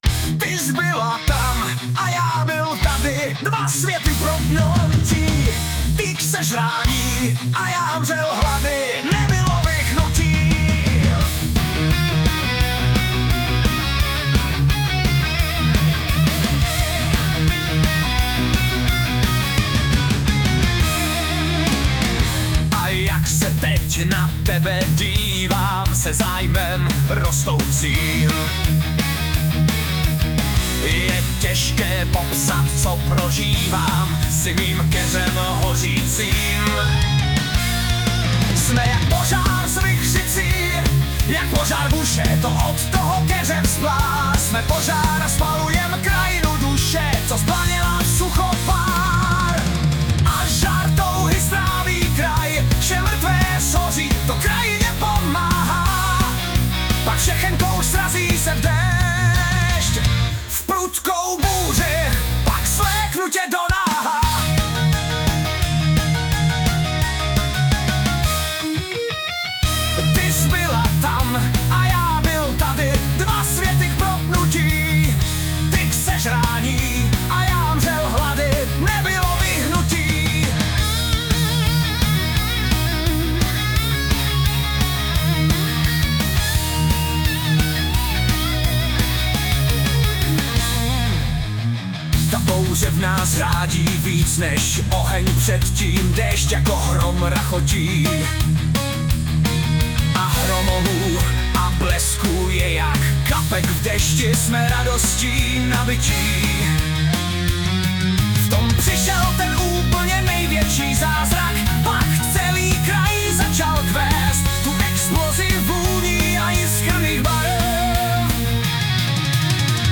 Anotace: Relativně nový písňový text. Upravený do podoby kompletní písně pomocí SUNO AI.